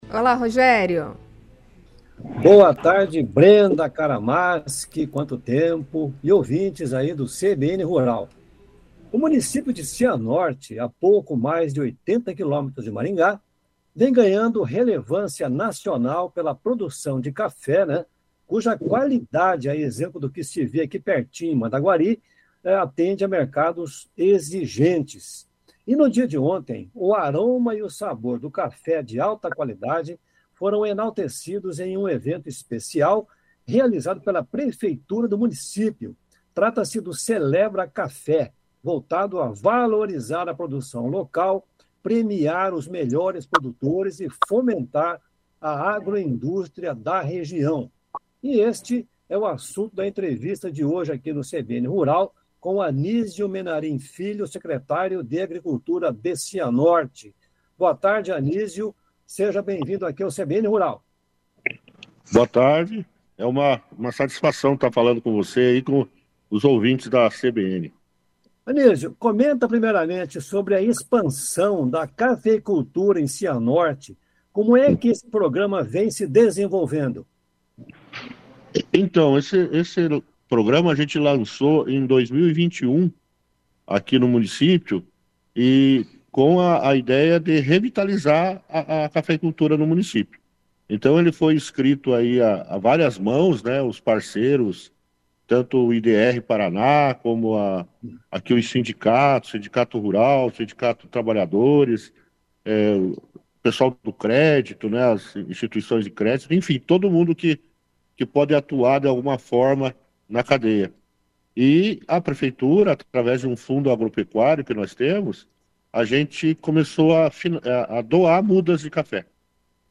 O CBN Rural conversou sobre isso com Anizio Menarim Filho, secretário de Agricultura de Cianorte.